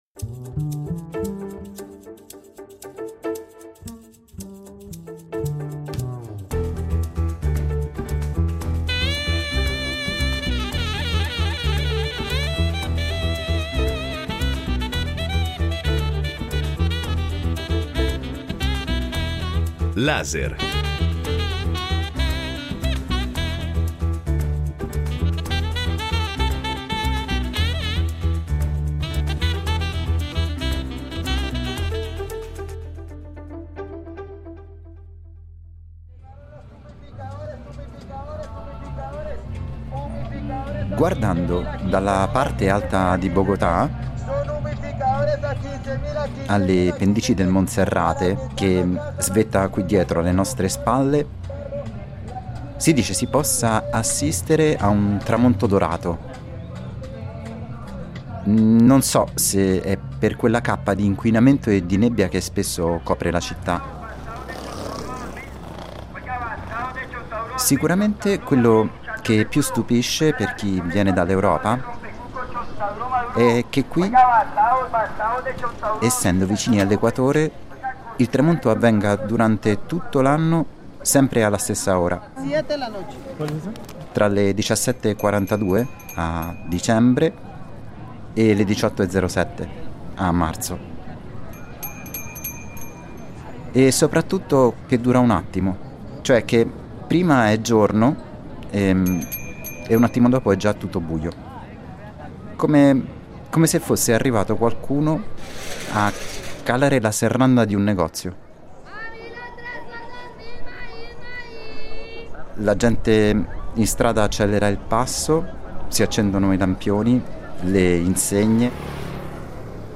Per saperne di più siamo stati negli studi di un programma radiofonico che da diversi anni accompagna i bogotani durante le ore piccole.